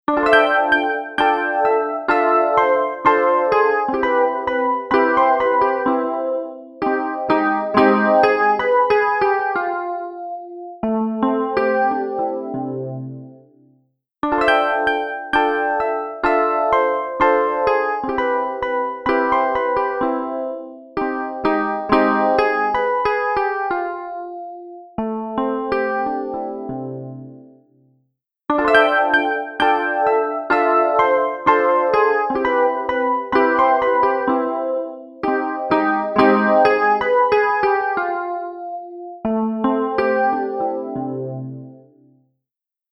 TriceraChorusは、サウンドに極上の豊かさと空間的な奥行きをもたらす、洗練されたコーラス・ソリューションです。
TriceraChorus | Electric Piano | Preset: Fauxverb
TriceraChorus-Eventide-E.-Piano-Fauxverb.mp3